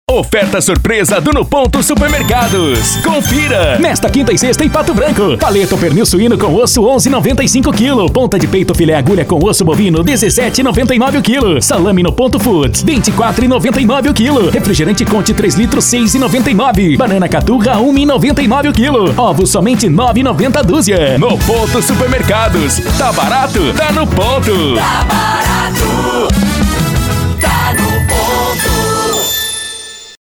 ESTILO VAREJO :